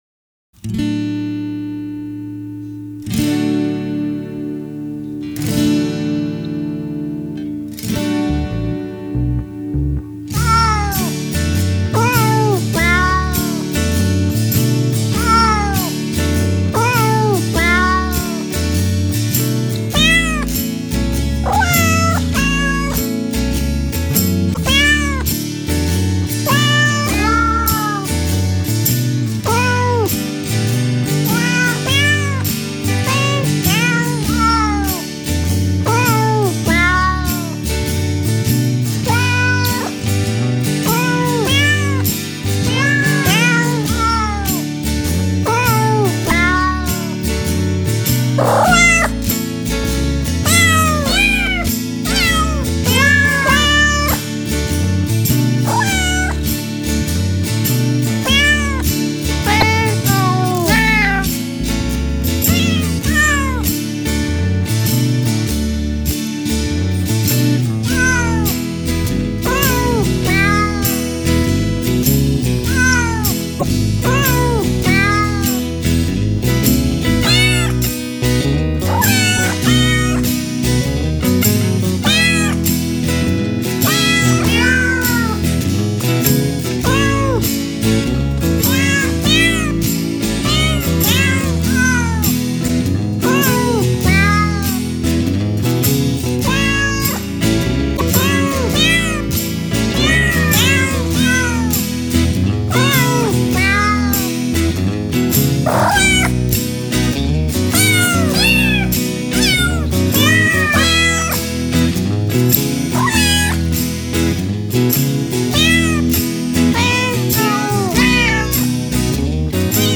用猫咪叫声合成的“平安夜”